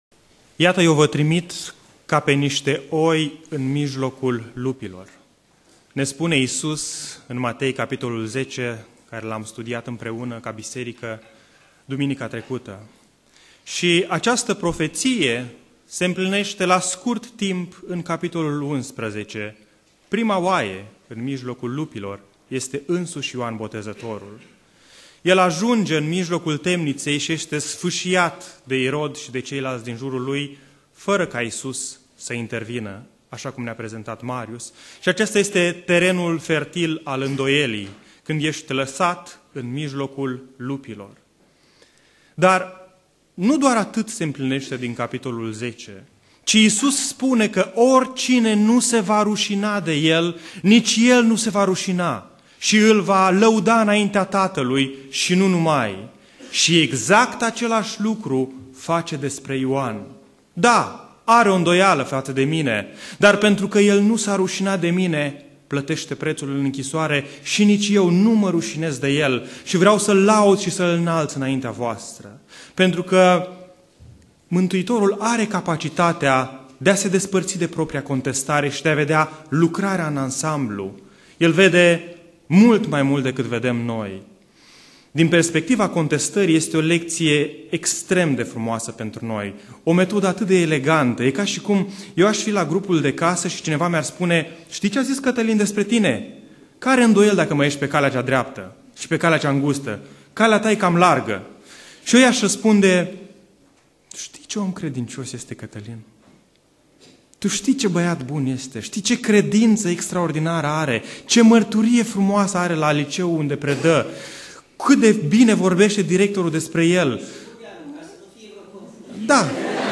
Predica Aplicatie - Matei 11